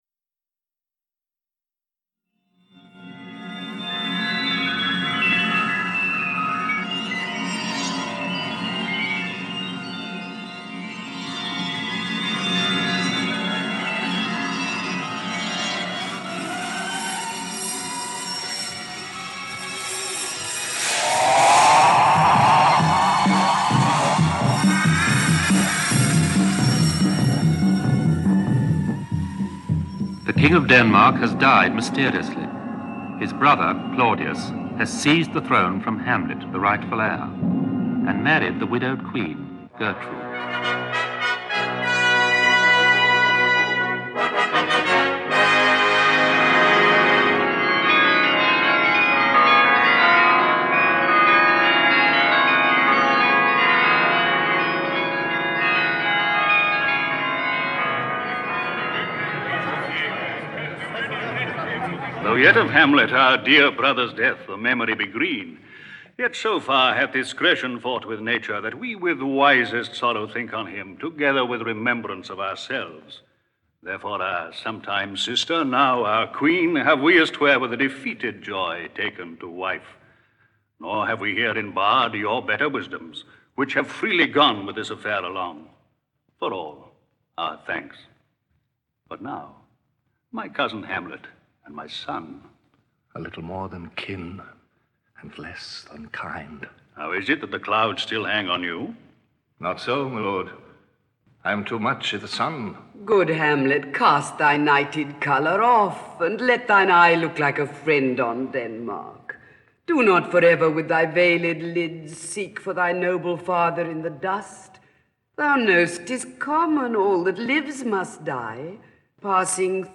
Ukázka z knihy
Adaptation and theatrical version of The Tragedy of Hamlet, Prince of Denmark - a tragedy by William Shakespeare.
• InterpretVarious